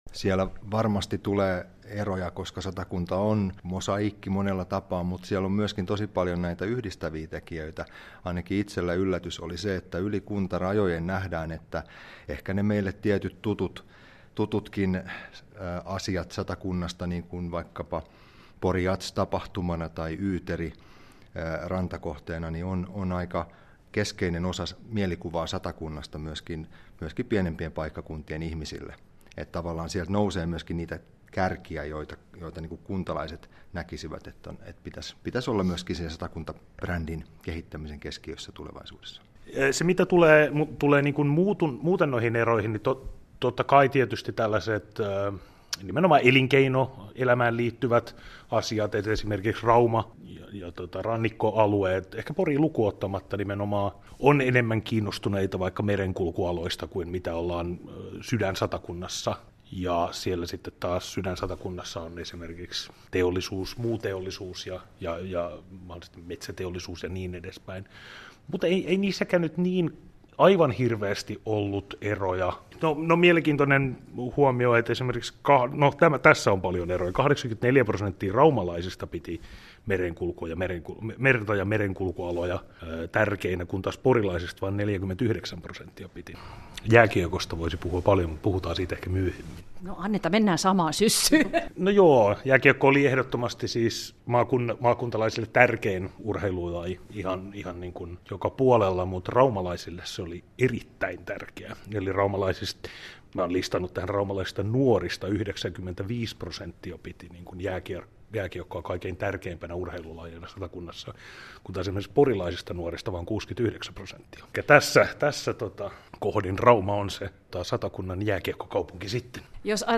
Satakuntalaisuuskyselyn tuloksia, osa 2 – YLE Porin haastattelu